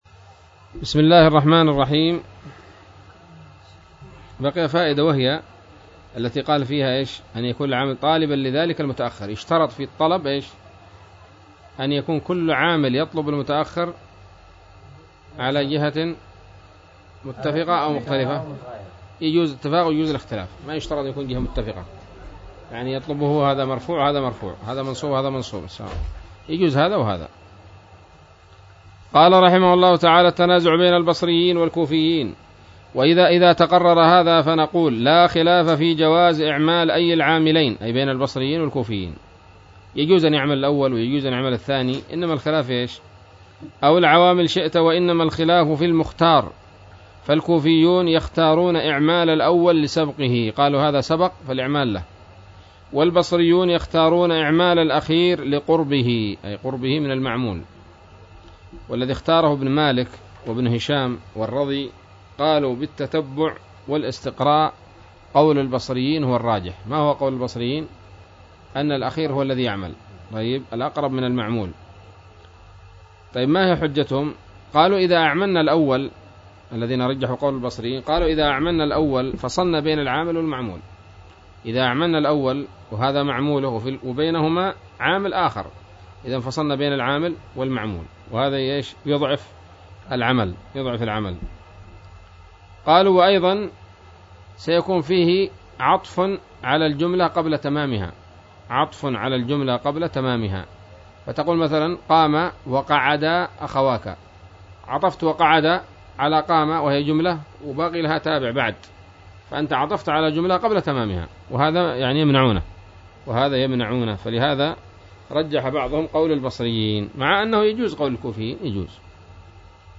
الدرس الثاني والثمانون من شرح قطر الندى وبل الصدى